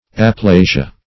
Aplasia \A*pla"si*a\, n. [NL.; Gr. ? priv. + ? a molding.]